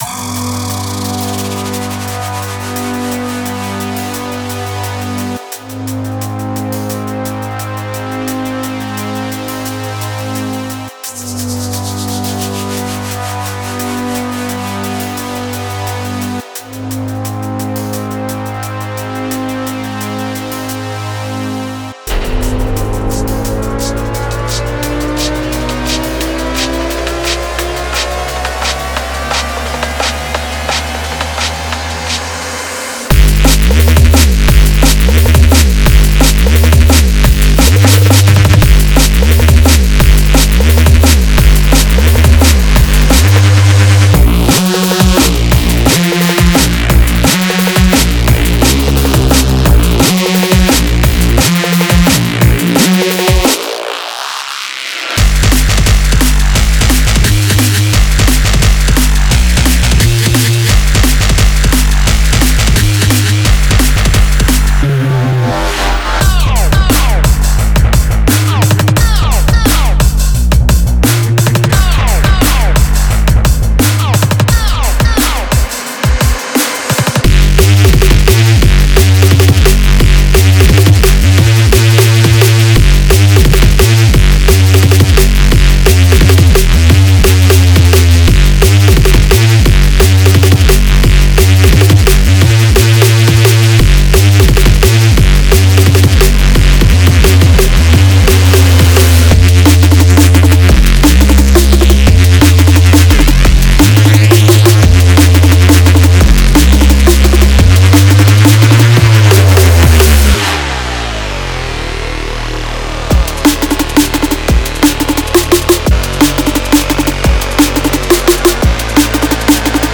Genre:Neurofunk
デモサウンドはコチラ↓